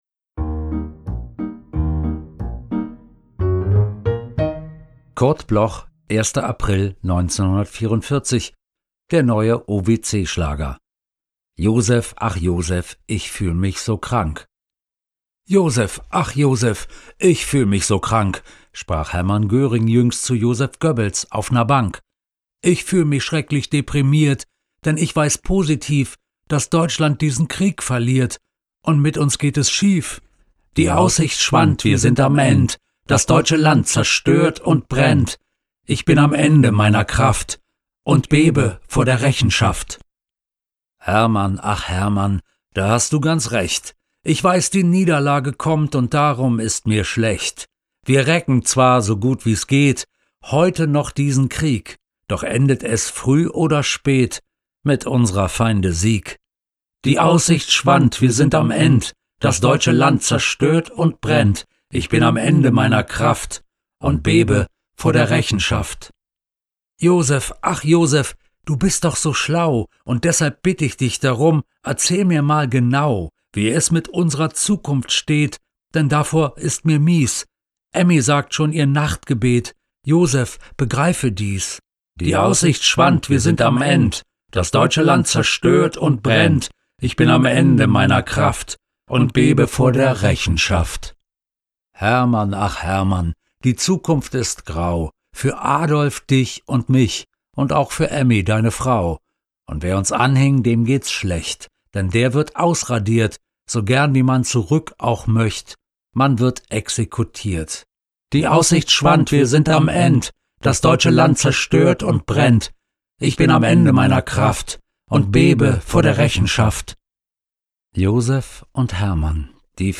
voorgedragen door Bela B Felsenheimer
Bela-B.-Joseph-ach-Joseph-mit-Musik.m4a